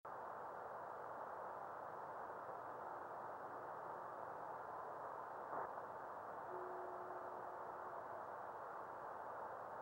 No obvious sonic reflection. Just background continuum.